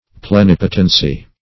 Plenipotency \Ple*nip"o*ten*cy\ (pl[-e]*n[i^]p"[-o]*ten*s[y^])